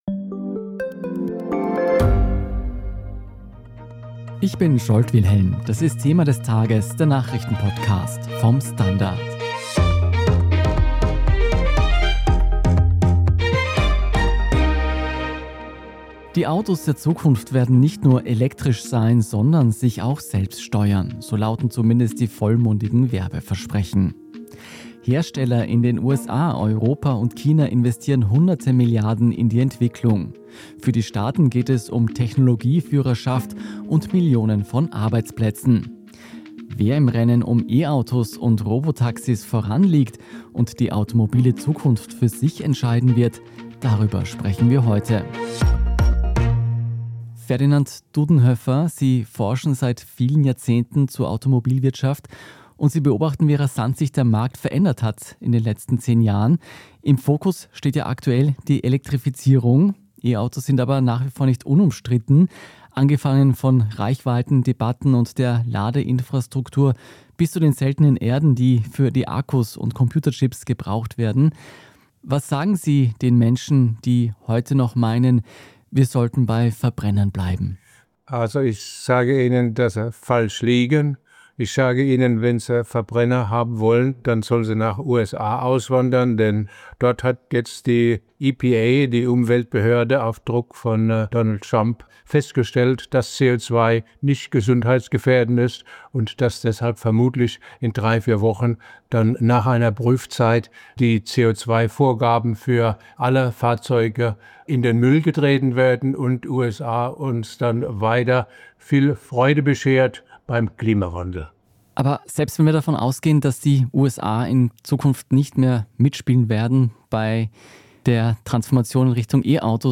Wieso Chinas E-Autos und Robotaxis alle überholen Thema des Tages Download Auto-Experte Ferdinand Dudenhöffer erklärt, wieso die automobile Zukunft nicht in Europa und schon gar nicht in den USA entschieden wird Die Autos der Zukunft werden nicht nur elektrisch sein, sondern sich auch selbst steuern.